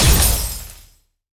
ZombieSkill_SFX
sfx_skill 08_2.wav